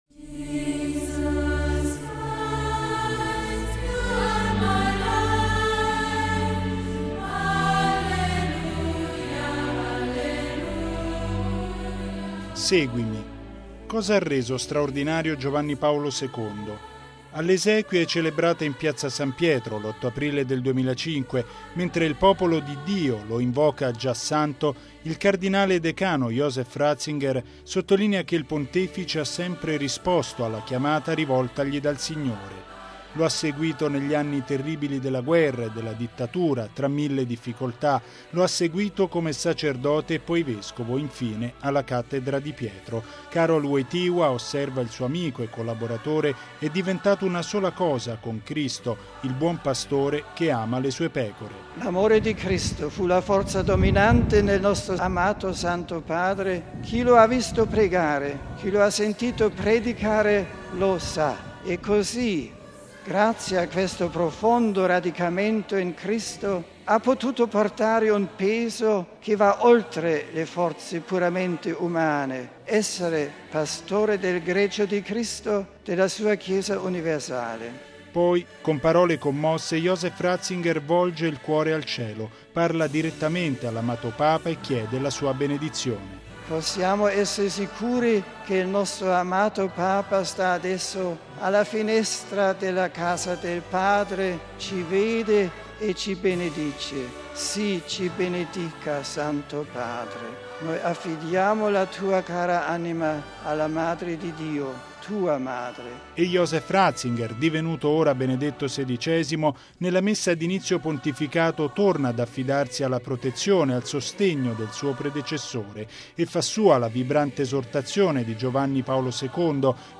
(Canto – Jesus Christ you are my life)
(Canto)